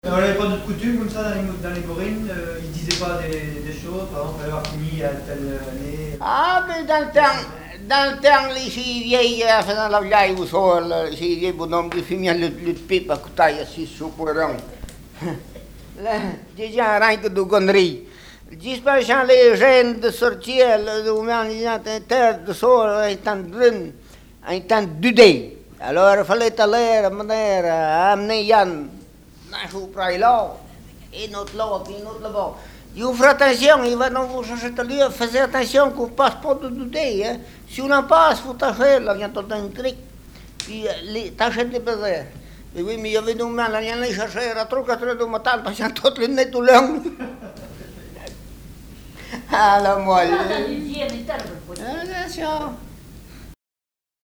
Langue Maraîchin
Catégorie Témoignage